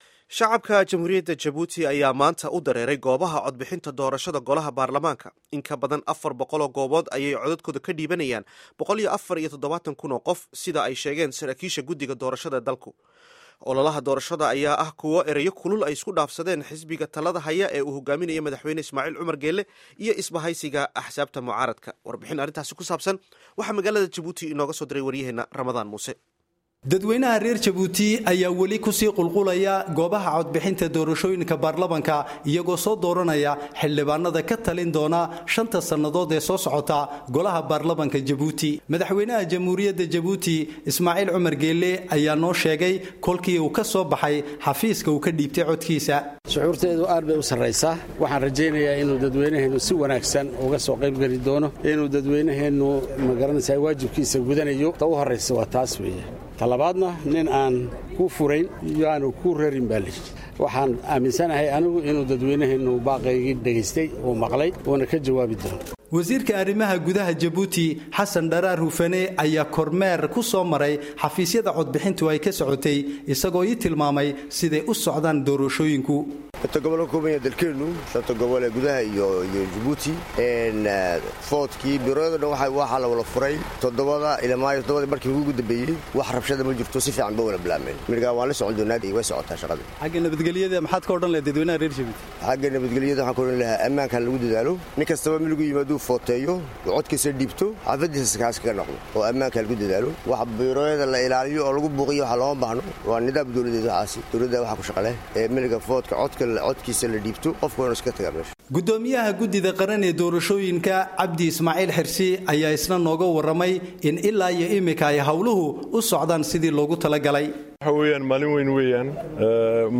Warbixinta Doorashada Baaralmaanka Jabuuti